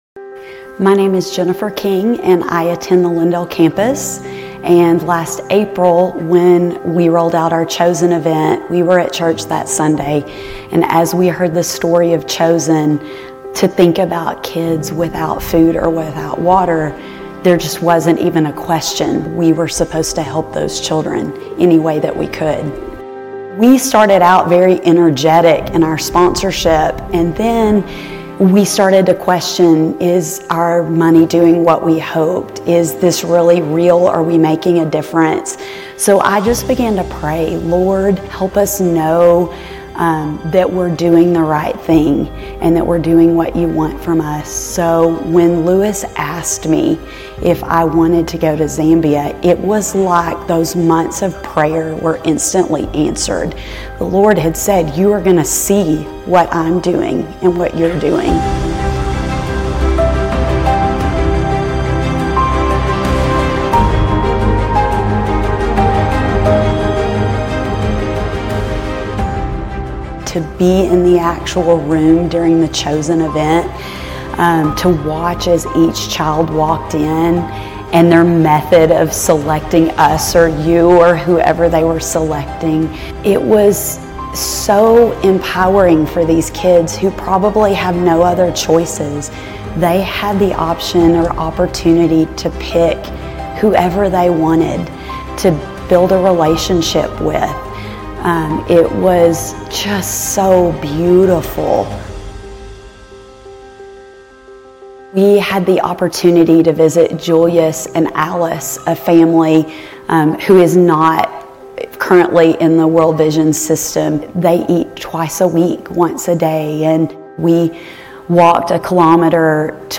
Grace Community Church University Blvd Campus Sermons Galatians 3:1-14 Salvation by Faith not Works Apr 29 2024 | 00:39:03 1x 00:00 / 00:39:03 Subscribe Share RSS Feed Share Link Embed